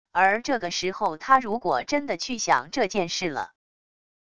而这个时候他如果真的去想这件事了wav音频生成系统WAV Audio Player